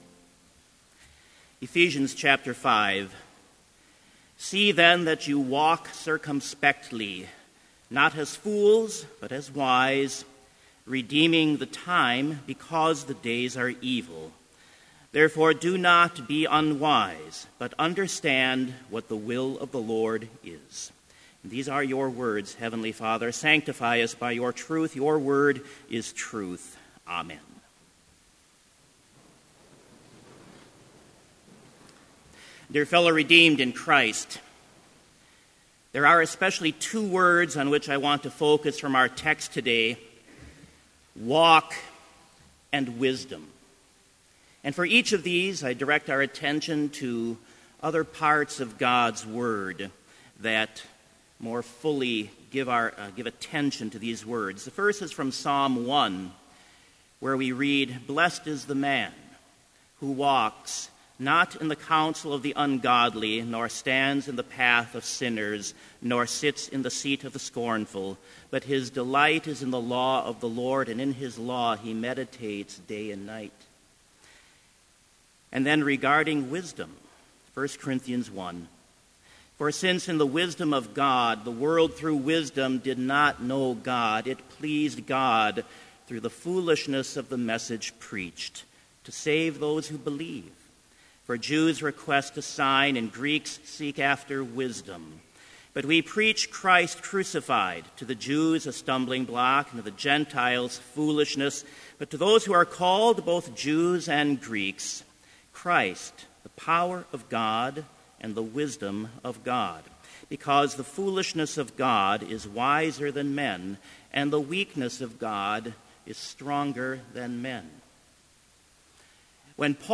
Complete Service
• Opening Prayer (read responsively)
• Hymn 252, vv. 1 & 3, I Walk in Danger All the Way
• Devotion
This Chapel Service was held in Trinity Chapel at Bethany Lutheran College on Tuesday, October 20, 2015, at 10 a.m. Page and hymn numbers are from the Evangelical Lutheran Hymnary.